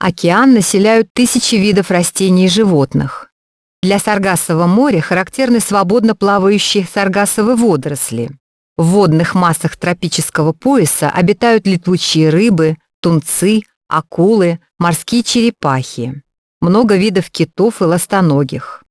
Звуковое сопровождение (Рассказ учителя).